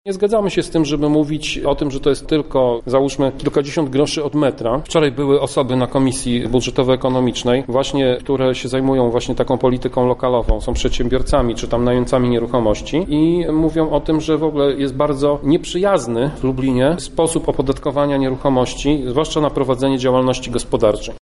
Przewodniczący radnych Prawa i Sprawiedliwości, Tomasz Pitucha, podkreśla, że jego klubowi zależy na utrzymaniu podatków na obecnym poziomie: